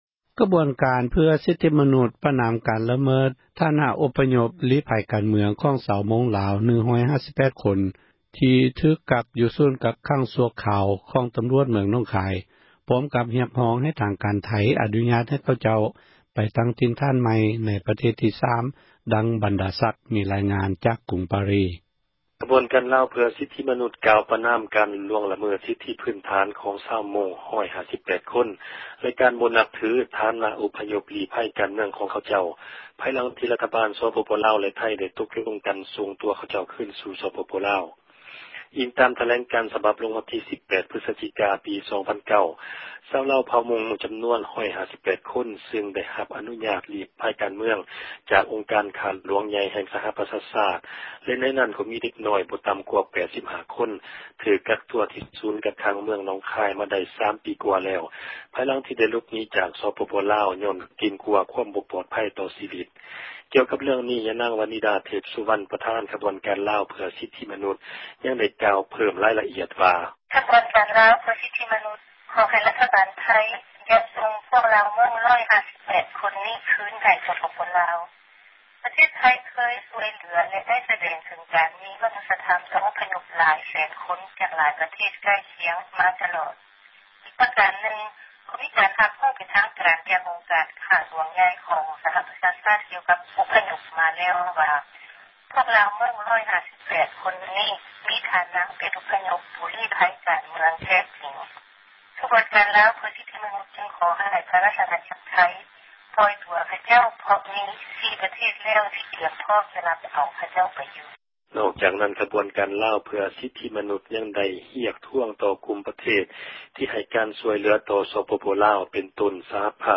ມີຣາຍງານ ຈາກກຸງປາຣີສ໌.....